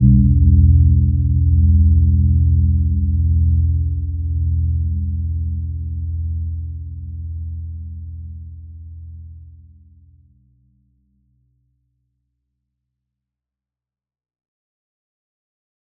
Gentle-Metallic-2-E2-p.wav